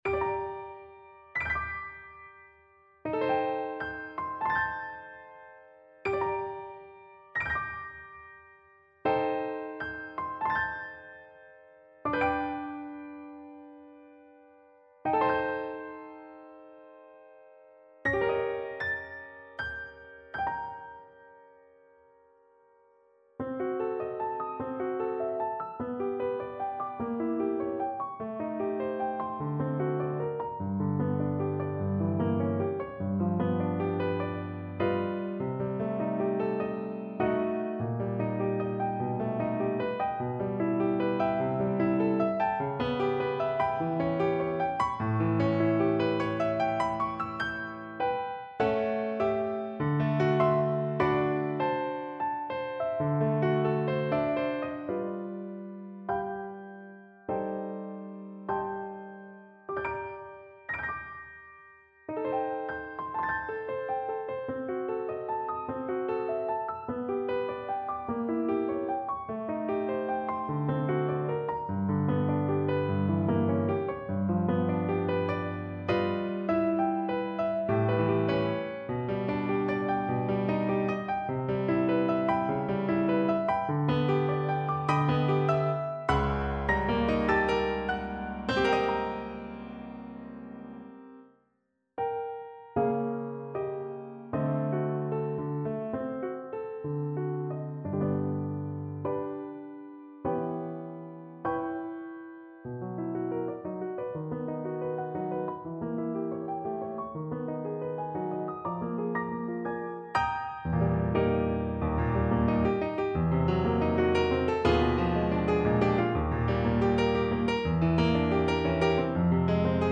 Flute version
4/4 (View more 4/4 Music)
Allegro ma non troppo =80 (View more music marked Allegro)
Classical (View more Classical Flute Music)